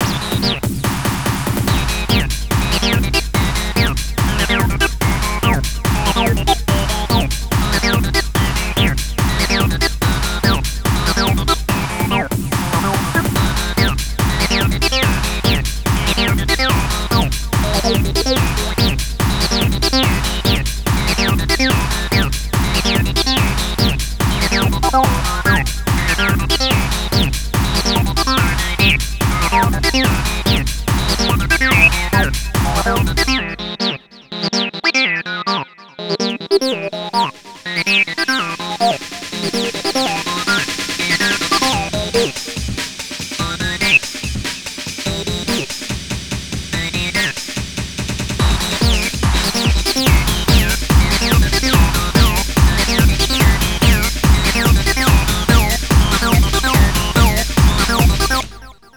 c'est de l'AcidChord Jungle, 143 BPM